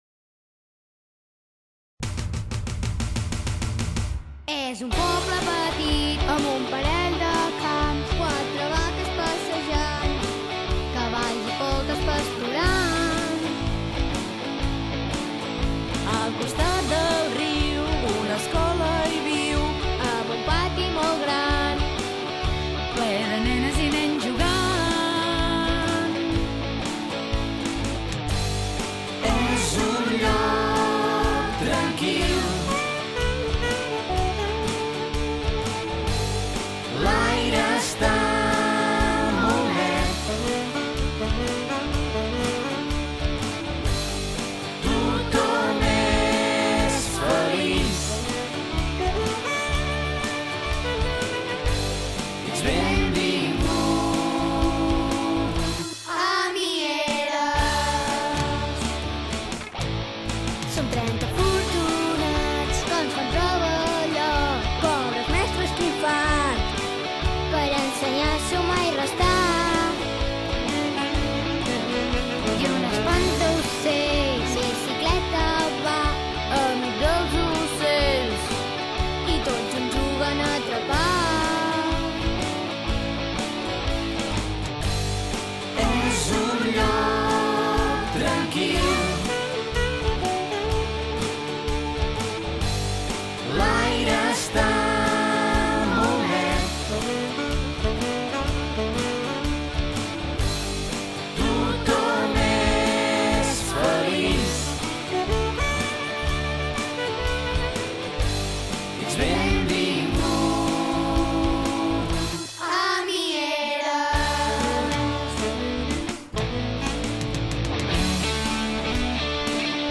ROCK DE l’ESCOLA DE MIERES: